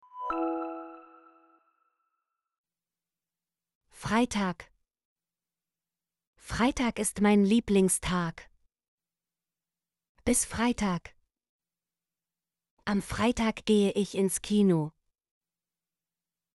freitag - Example Sentences & Pronunciation, German Frequency List